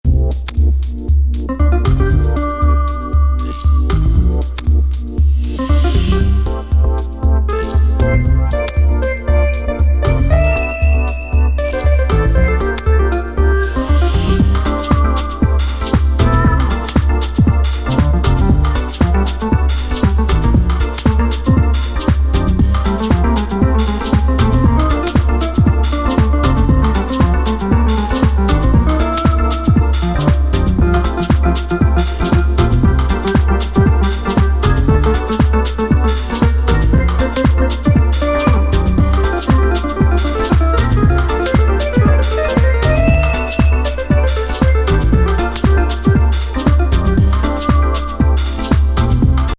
コーストなA面が◎